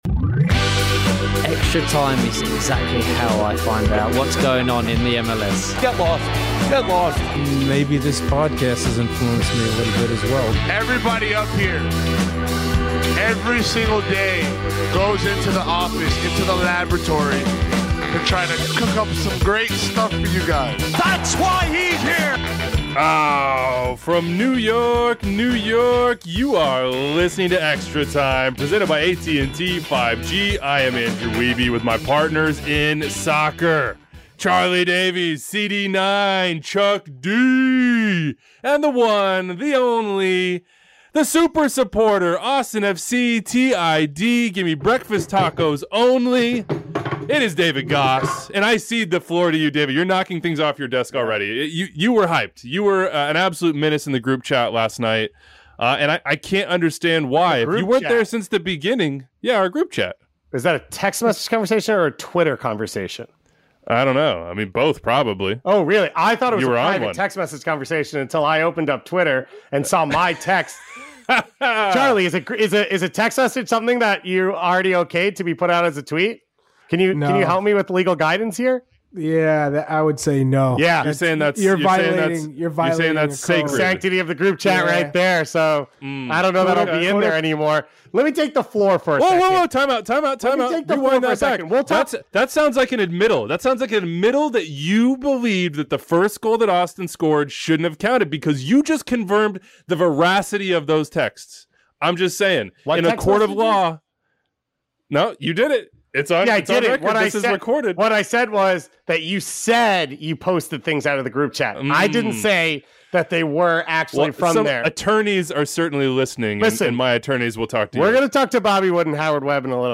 Plus, Howard Webb drops by to update you on VAR in MLS, and Bobby Wood joins the show to talk Real Salt Lake, USMNT and AAPI Heritage Month.